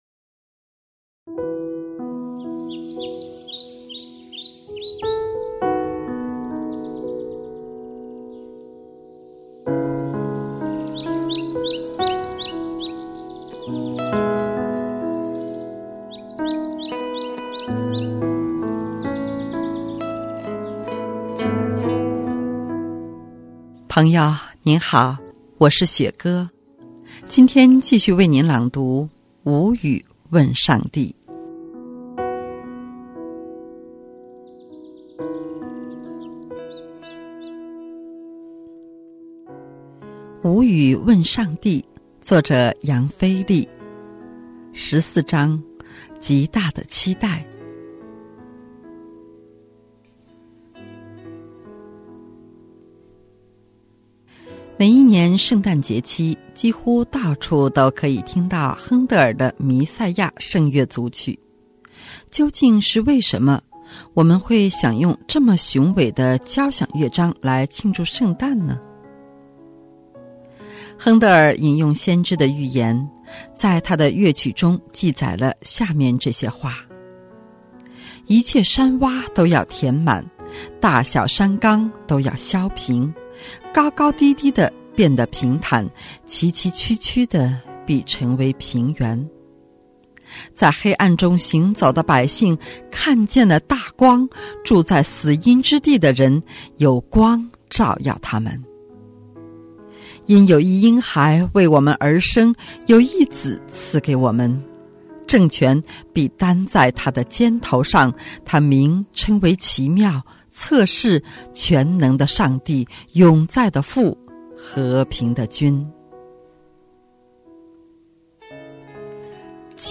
今天继续为您朗读《无语问上帝》。 无语问上帝，作者：杨腓力。